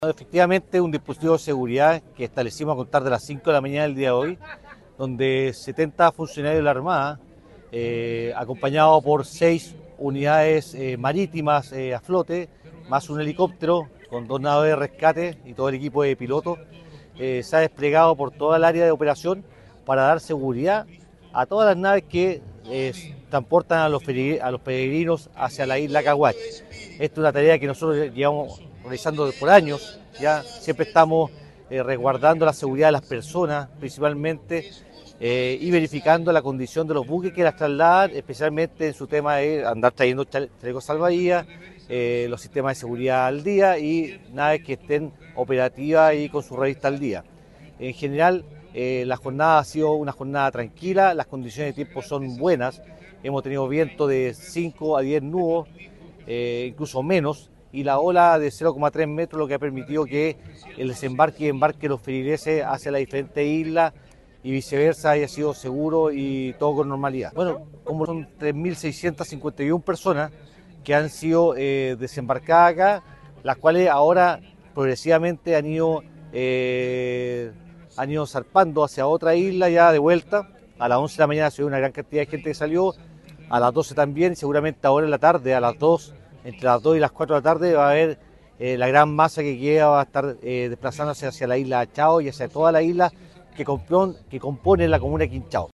El gobernador marítimo de Castro, Ricardo Cárcamo informó del despliegue del dispositivo de seguridad que contó con 56 servidores navales, 4 Unidades Marítimas y 1 helicóptero del Grupo Aeronaval Puerto Montt.
01-GOBERNADOR-MARITIMO.mp3